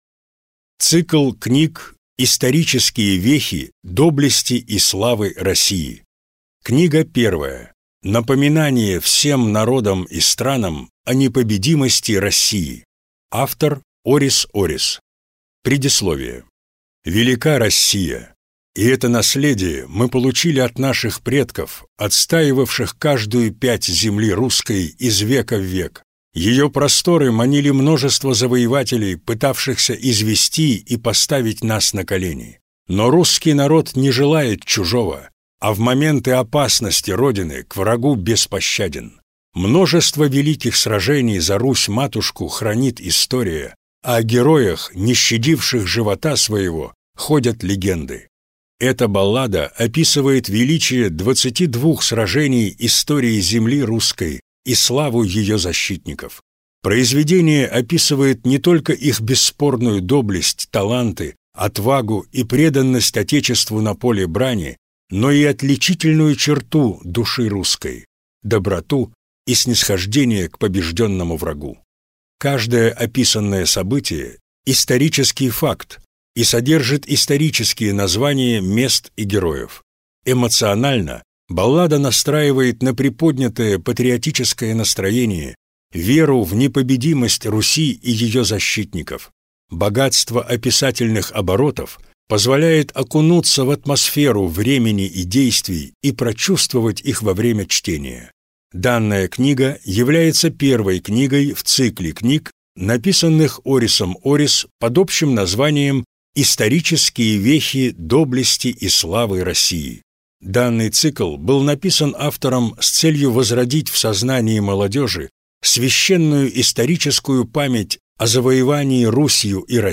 Аудиокнига Напоминание всем народам и странам о непобедимости России | Библиотека аудиокниг
Прослушать и бесплатно скачать фрагмент аудиокниги